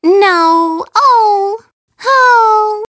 One of Princess Peach's voice clips in Mario Kart 7